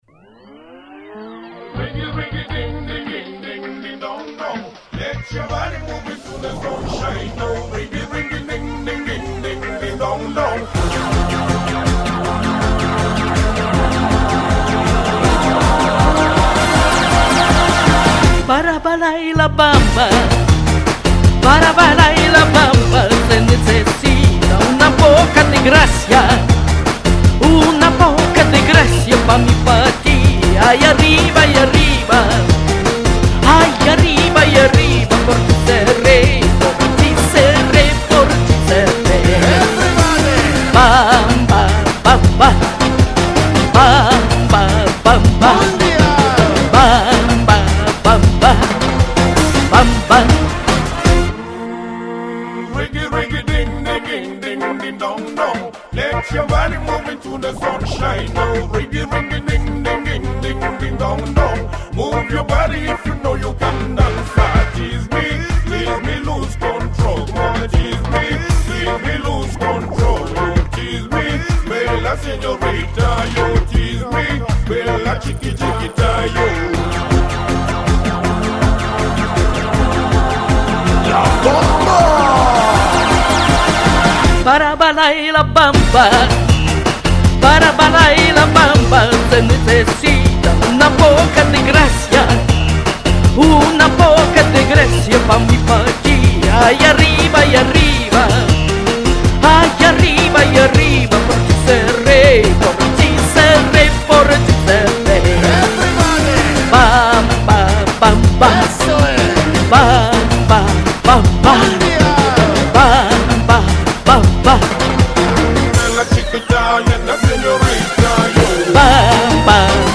А какие мужчины жаркие на подпевках и подтанцовках, уууухххх!!!!!